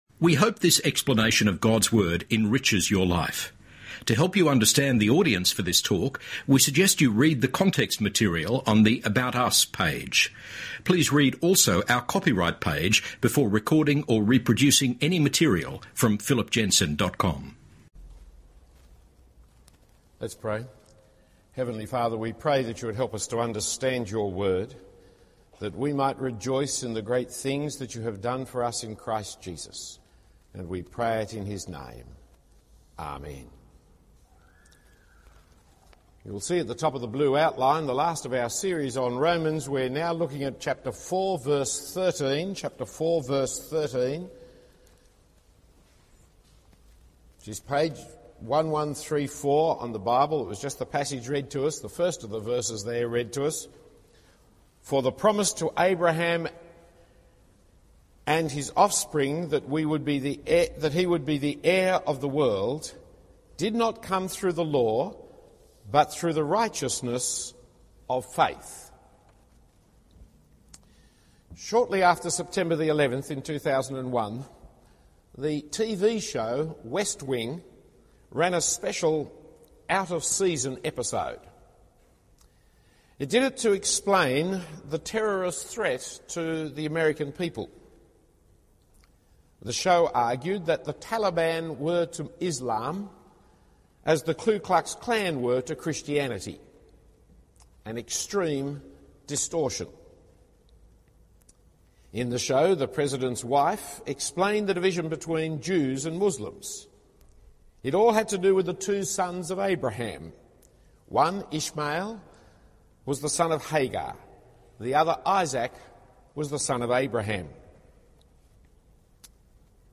Bible Passages Romans 4:13 Series Romans 2006-7 St Andrews Cathedral Contexts St Andrew's Cathedral Date 27 Aug 2006 Type Audio Share & More Download Audio